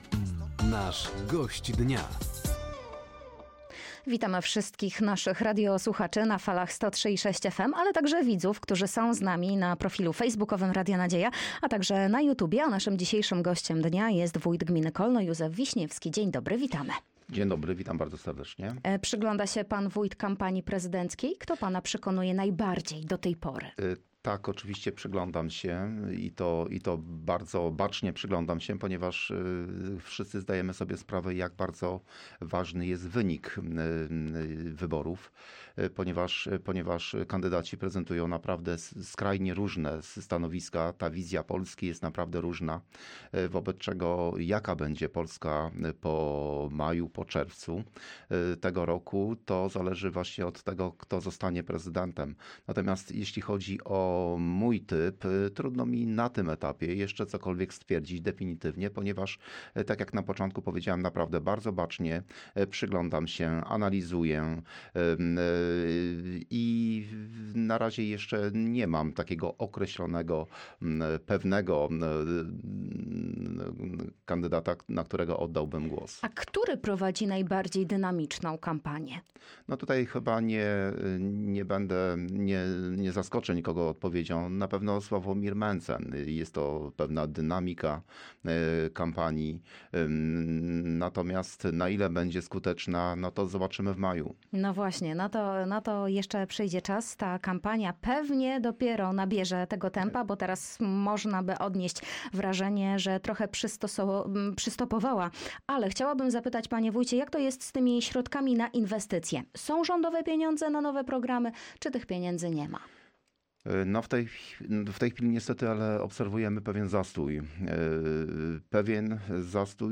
Trwająca kampania prezydencka, szkolenie samorządowców z zakresu ochrony ludności i obrony cywilnej, a także oficjalne otwarcie Centrum Opiekuńczo-Mieszkalnego w Glinkach – to główne tematy rozmowy z Gościem Dnia Radia Nadzieja.
O sprawach ważnych dla mieszkańców gminy Kolno mówił wójt Józef Wiśniewski.